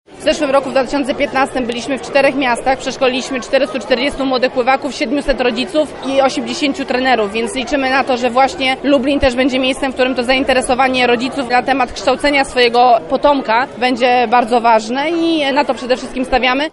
– W ubiegłym roku treningi odniosły sukces – mówi mistrzyni olimpijska Otylia Jędrzejczak: